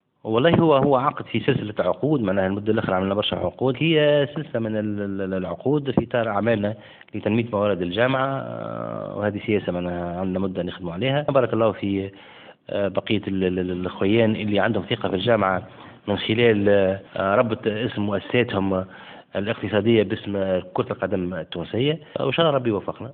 وديع الجريء : رئيس الجامعة التونسية لكرة القدم